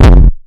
Index of /m8-backup/M8/Samples/breaks/breakcore/earthquake kicks 2
hammer kick.wav